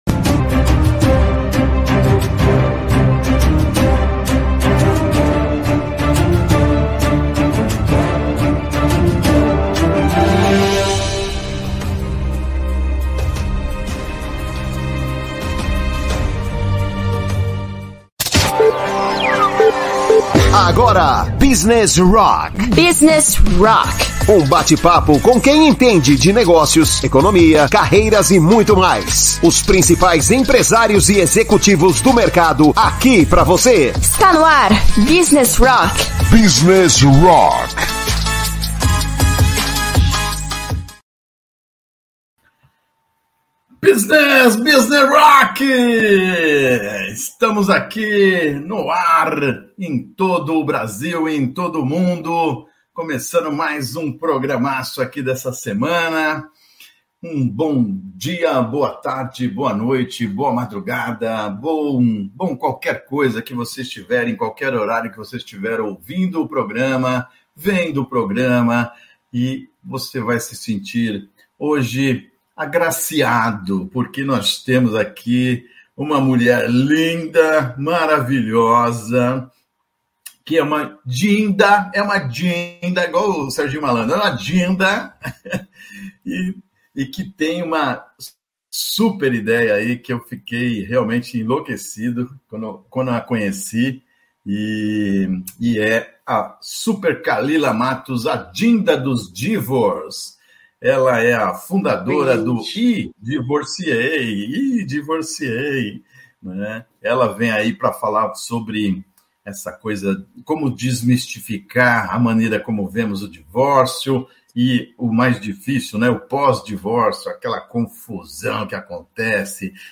Não perca esta conversa inspiradora e cheia de insights.